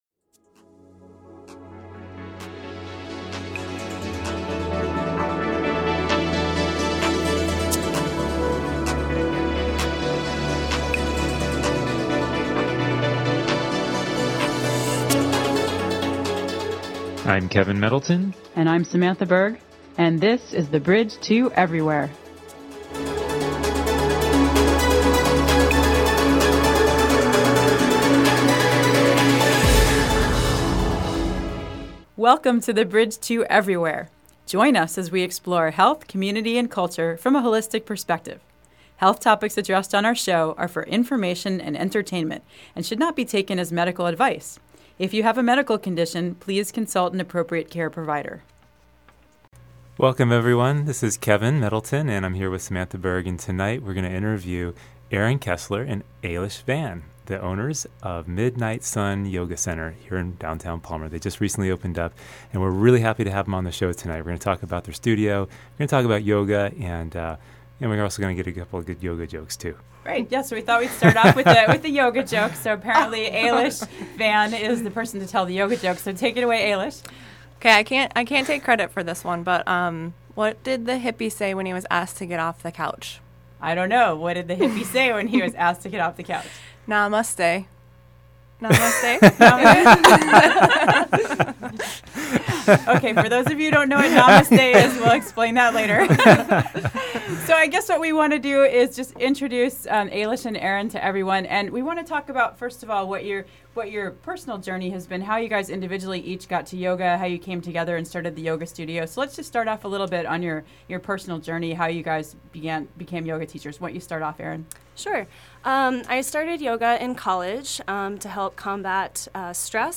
Here is a recording of the full interview.